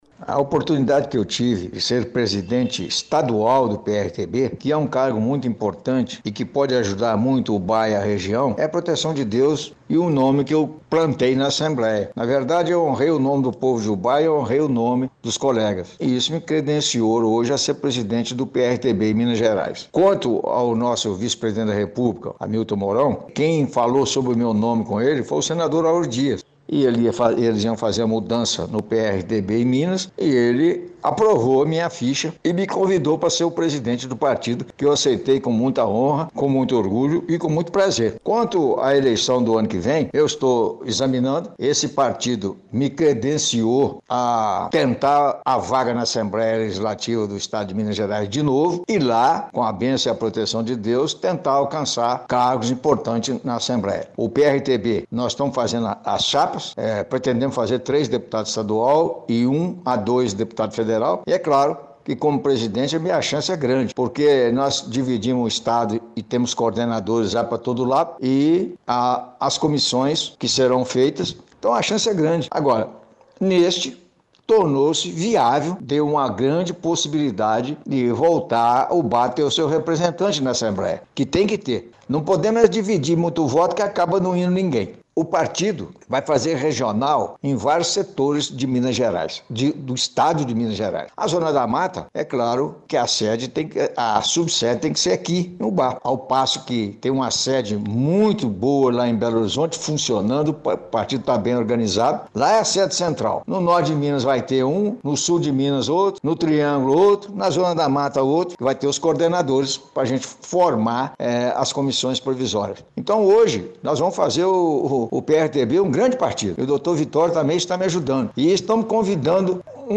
Dirceu Ribeiro falou do convite e dos objetivos à frente da presidência